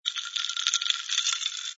sfx_ice_moving06.wav